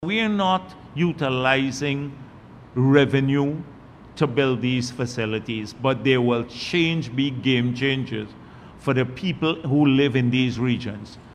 During a recent press conference, Dr. Jagdeo addressed concerns about the project’s development, expressing confidence in its progress.